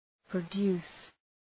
Προφορά
{prə’du:s}